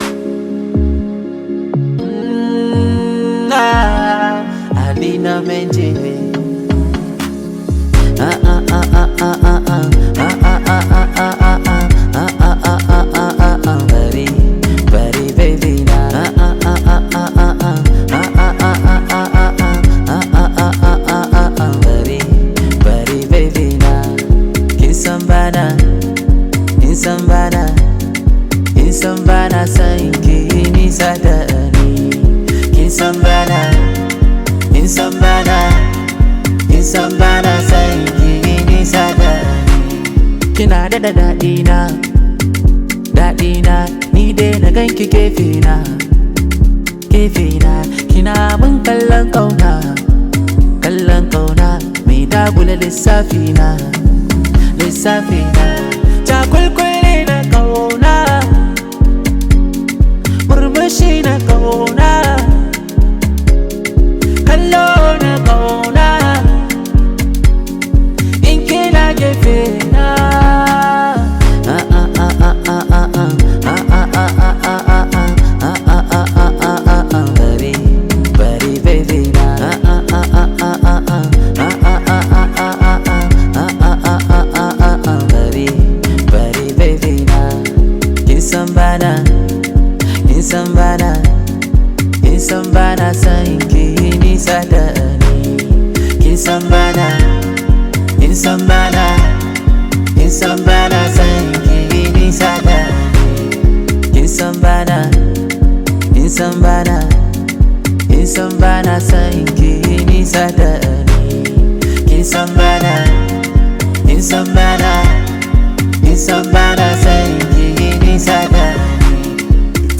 high vibe hausa song